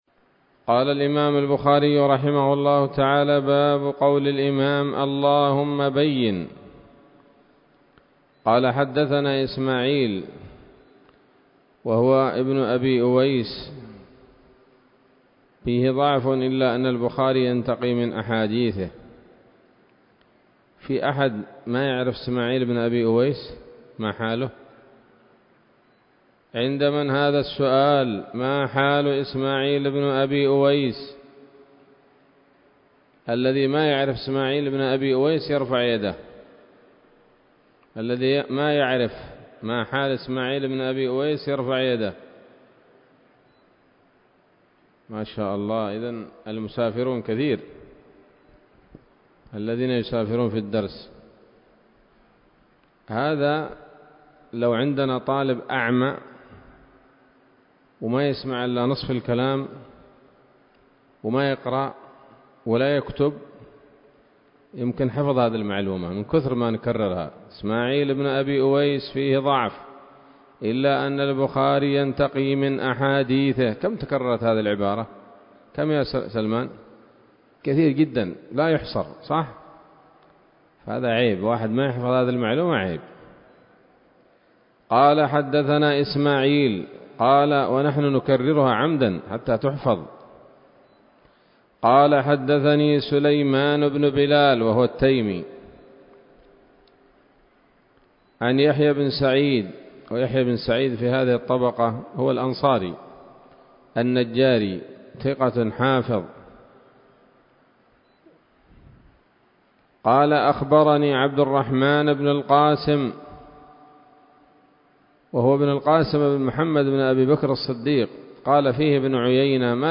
الدرس السابع والعشرون من كتاب الطلاق من صحيح الإمام البخاري